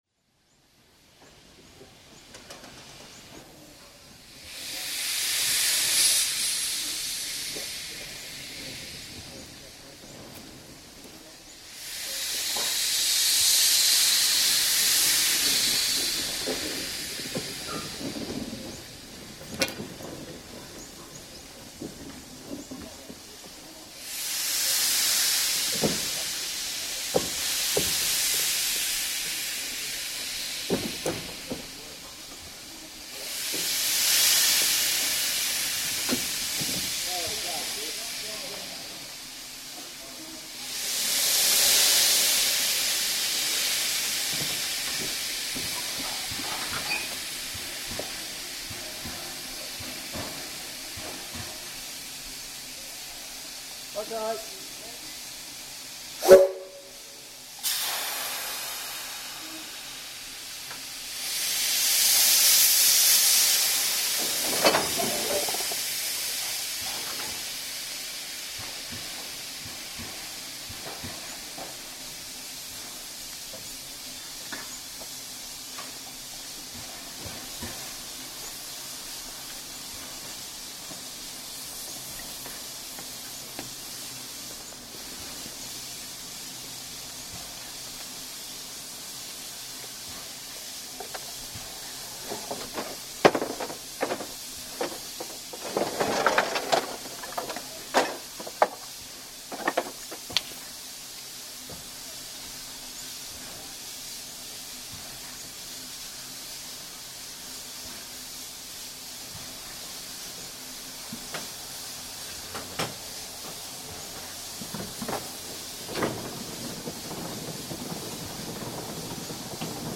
Note I have faded these in and out just to go easy on your ears.
2 - Shunting Steam 1 - 13 min 18 secs
For your country station scene, we have some sounds recorded of J Class 541 preparing her train for the day's run on the VGR.  Listen to the engine run back and forth, the crew having a chat and the guard performing his duties.  This track is well suited to a quieter country station.
Shunting_Steam_web.mp3